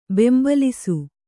♪ bembalisu